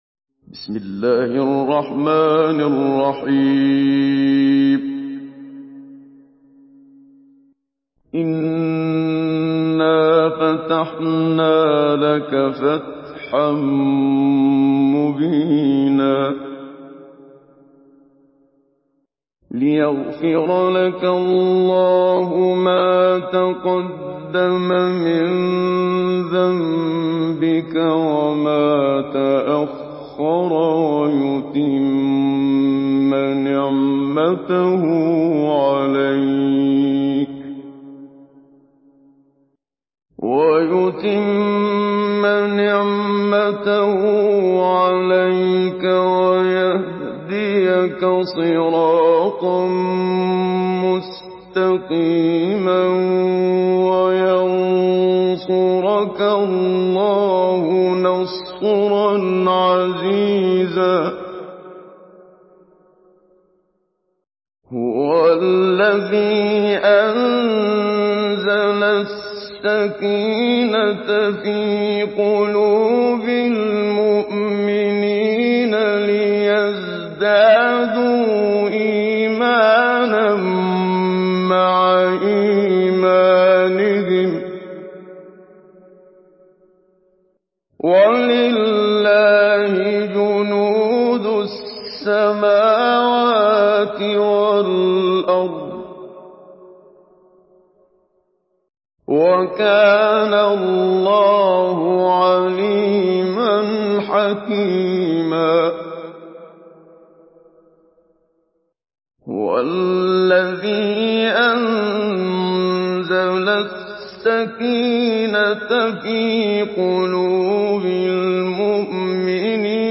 Surah Al-Fath MP3 in the Voice of Muhammad Siddiq Minshawi Mujawwad in Hafs Narration
Surah Al-Fath MP3 by Muhammad Siddiq Minshawi Mujawwad in Hafs An Asim narration.